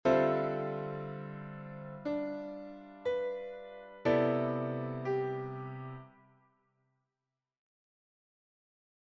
Improvisation Piano Jazz
Accords sus4